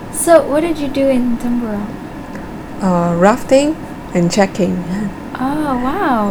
S1 = Brunei female S2 = Chinese female Context: They are talking about outdoor exercise, such as walking through the forest.
The word begins with [tʃ] rather than [tr] .